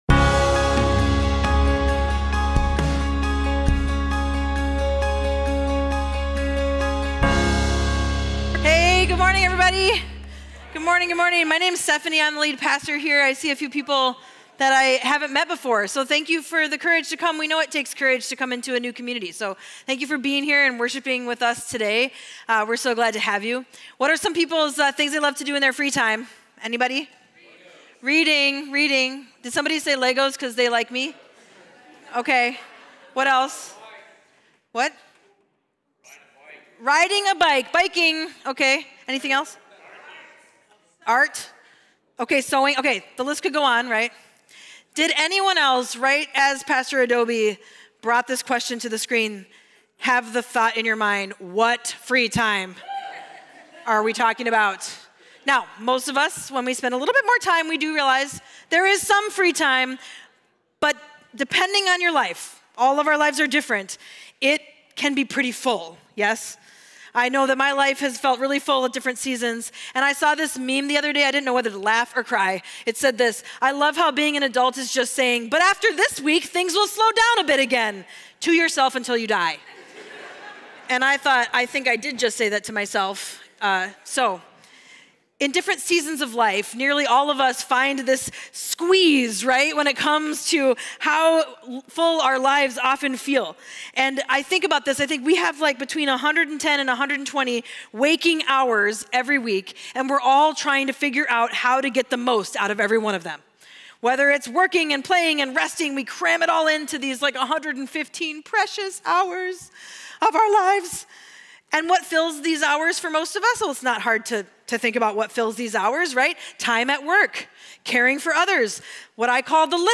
Mill City Church Sermons Apprenticing Jesus in Vocation Jun 17 2024 | 00:36:56 1x 00:00 / 00:36:56 Subscribe Share RSS Feed Share Link Embed